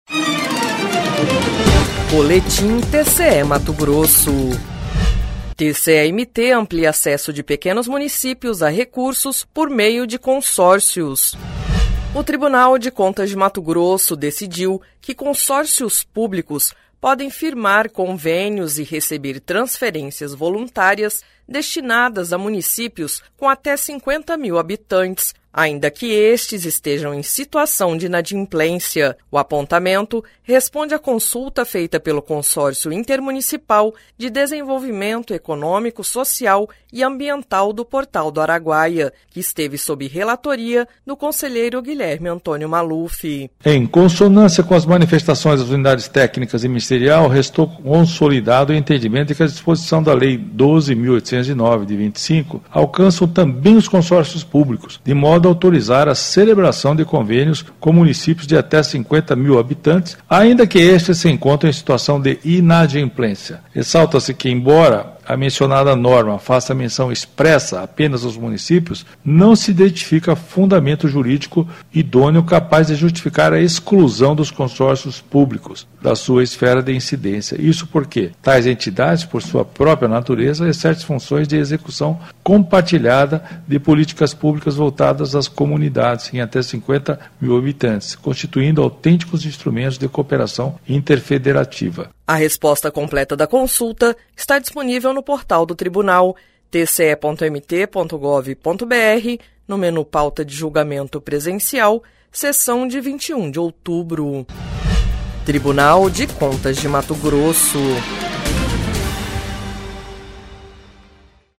Sonora: Guilherme Antonio Maluf – conselheiro do TCE-MT